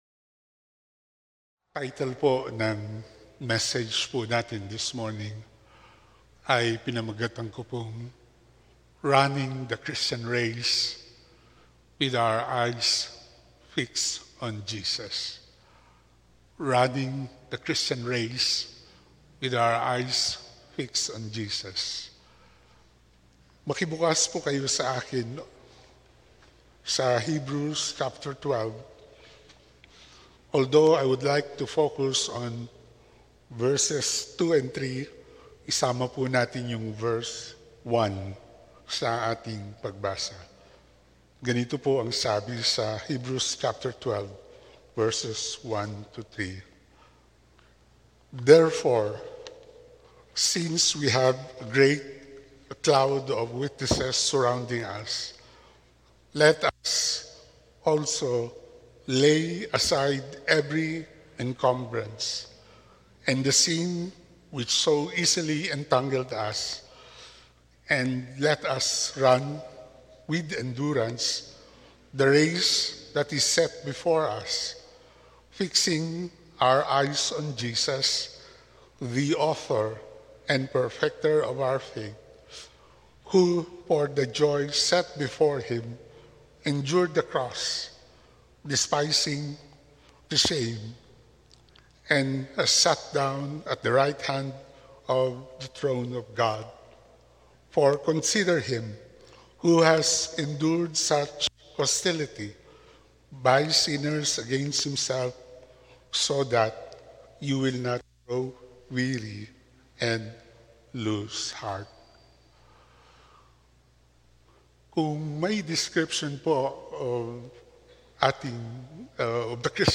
8 A.M Service